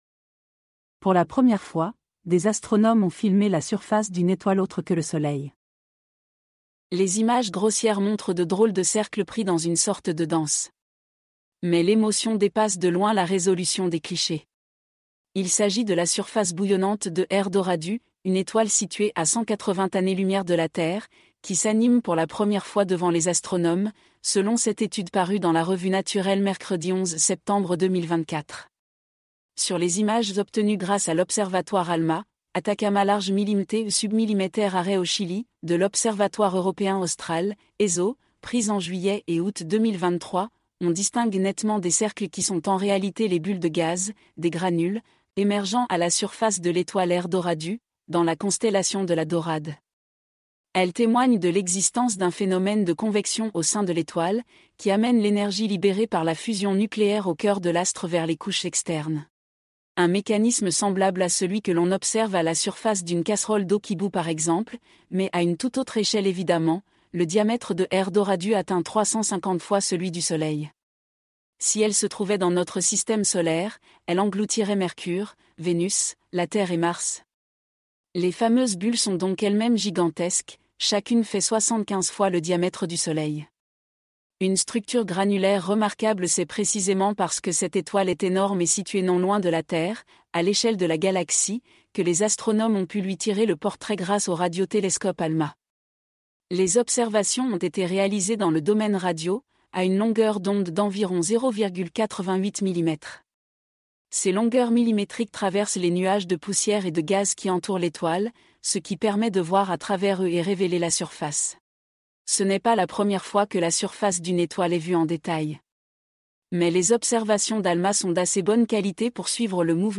Nos voix
Voix claires et naturelles, idéales pour des textes et narrations simples. Elles s’adaptent à une vaste gamme d’applications, assurant une synthèse de haute qualité, notamment pour les assistants virtuels, la lecture de nouvelles et les livres audio standard.